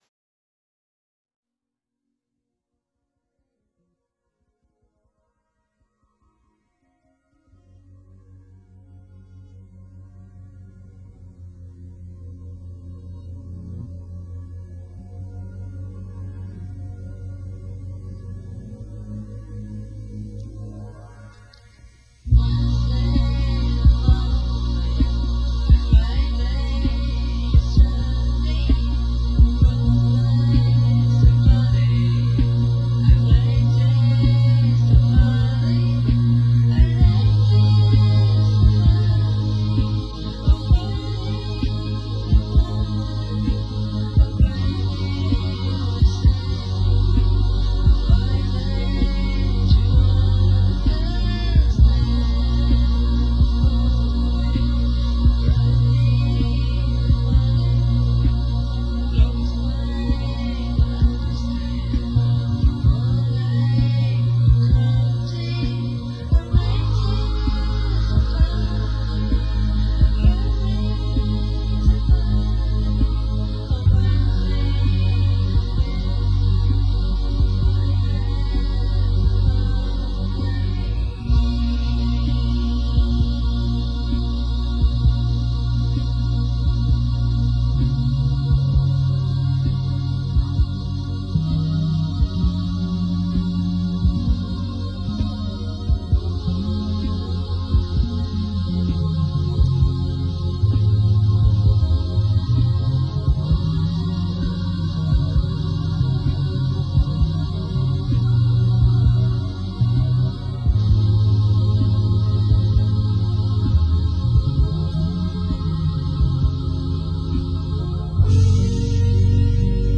当時後輩から強制的に借りていたRoland JUNO60というアナログシンセでキングクリムゾンの世界を狙ったのだけど・・歌詞は常套手段ですが、マザーグースの英語を適当にあてています。